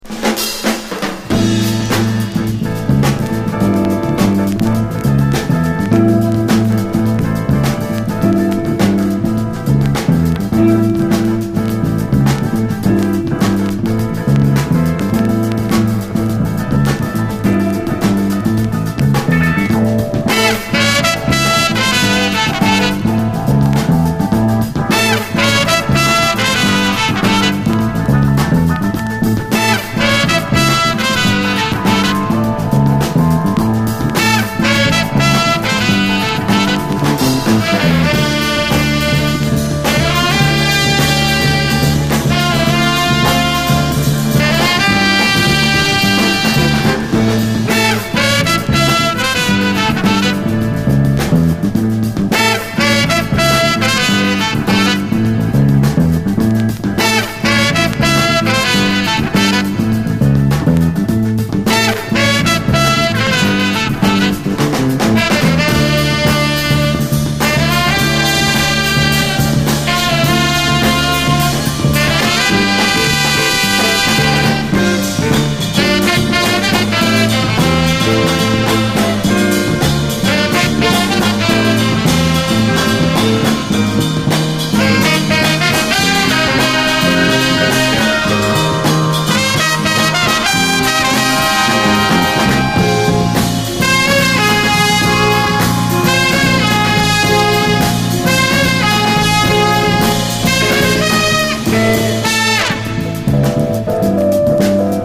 SOUL, 70's～ SOUL, 7INCH
メロウ・ファンク・クラシック